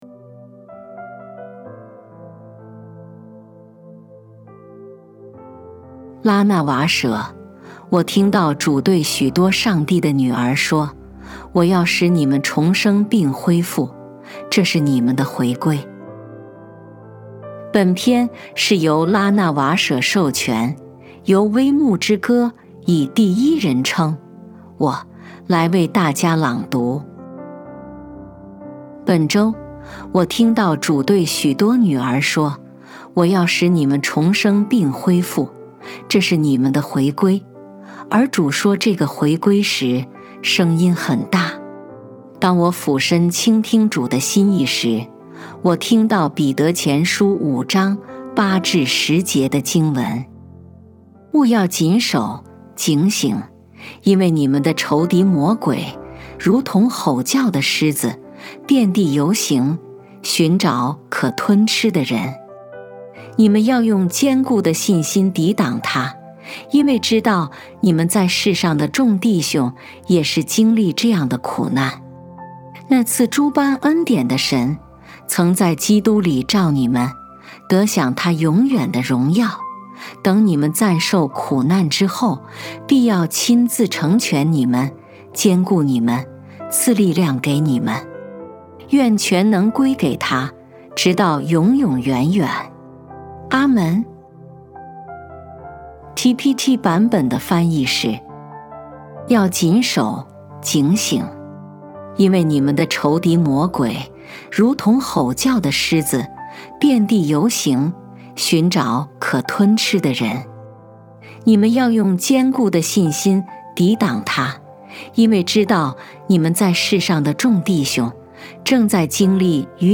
为大家朗读 我要使你们重生并恢复，这是你们的回归